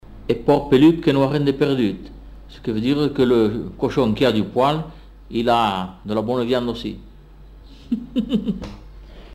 Aire culturelle : Comminges
Lieu : Bagnères-de-Luchon
Effectif : 1
Type de voix : voix d'homme
Production du son : récité
Classification : proverbe-dicton